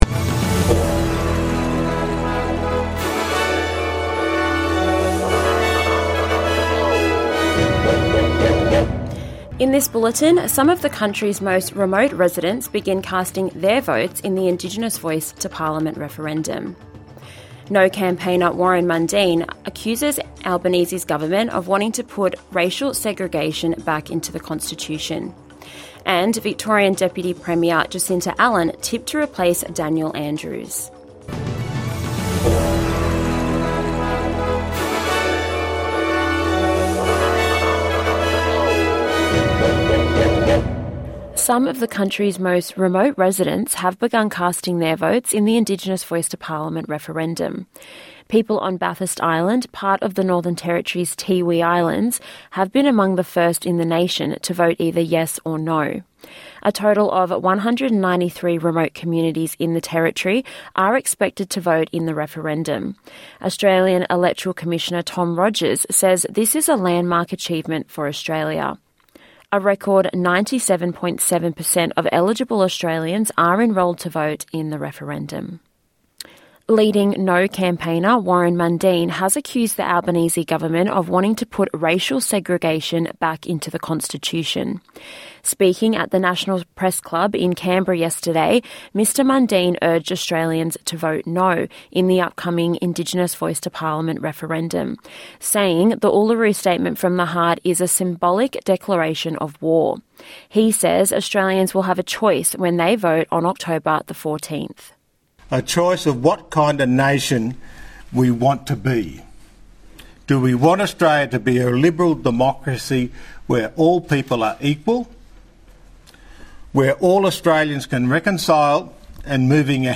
NITV Radio - News 27/09/2023